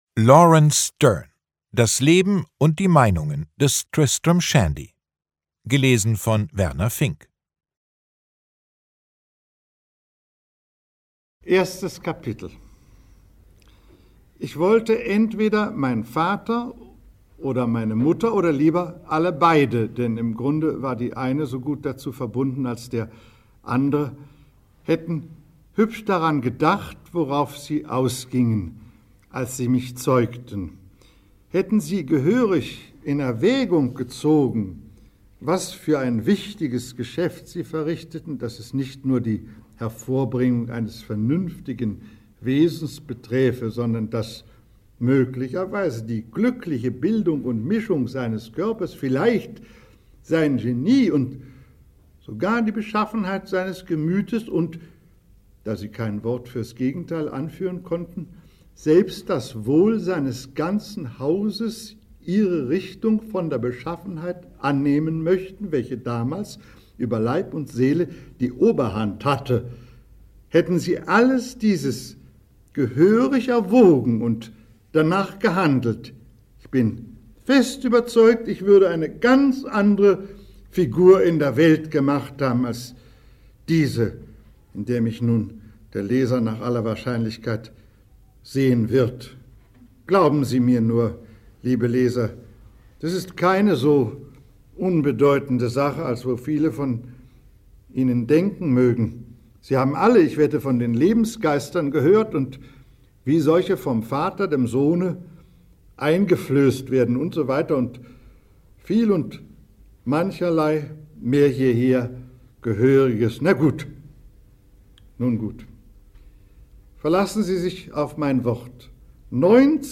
Werner Finck (Sprecher)
2021 | Gekürzte Lesung
Sich immer wieder in Abschweifungen verlierend und mit tausenderlei Anspielungen gespickt, erzählt Sterne sprunghaft und ganz und gar unvollständig das Leben seines Protagonisten Tristram Shandy und schuf damit den Ur-Roman der Moderne – unverwechselbar gelesen von Werner Finck.